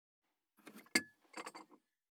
186,テーブル等に物を置く,食器,グラス,コップ,工具,小物,雑貨,コトン,トン,ゴト,ポン,ガシャン,ドスン,ストン,カチ,
コップ効果音物を置く
効果音